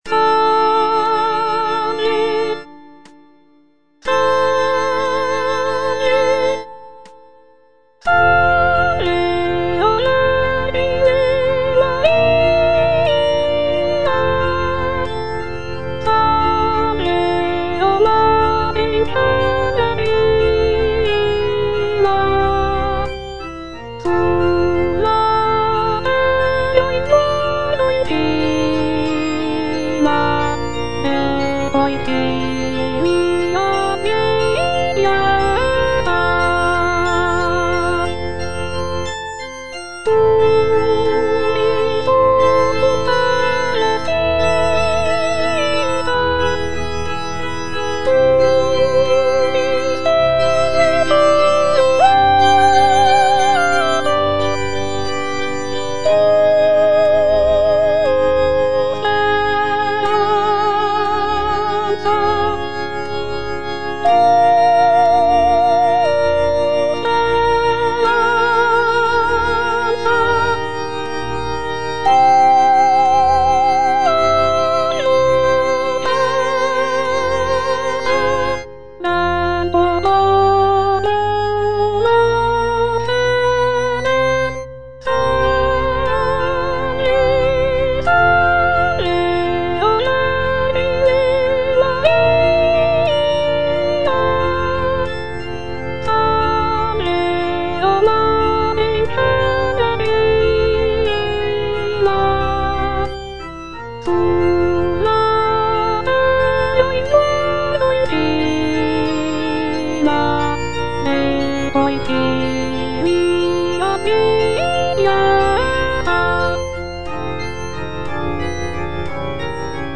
"Salve o vergine Maria" is a choral piece composed by Gioachino Rossini in 1831. It is a prayer to the Virgin Mary, and it is often performed during religious ceremonies. The music is characterized by its serene and devotional atmosphere, with lush harmonies and expressive melodies.